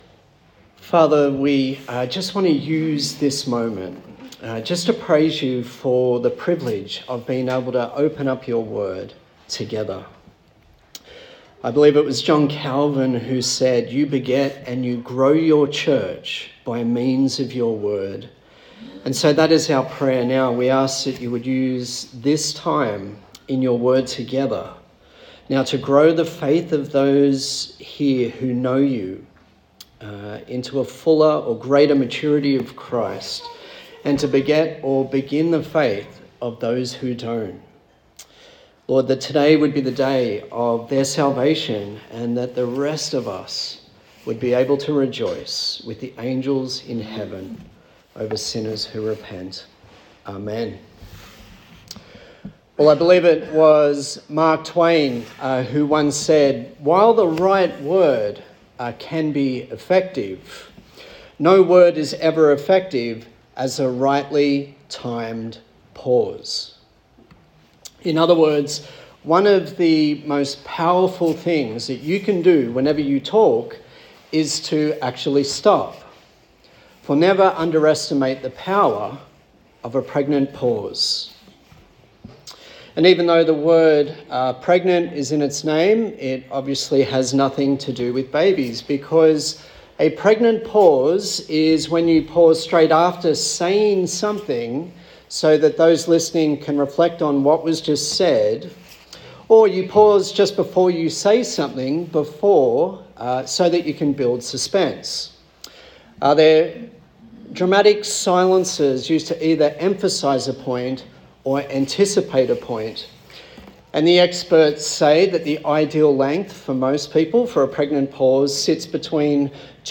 Kingdom Come Passage: Matthew 1:1-17 Service Type: Sunday Morning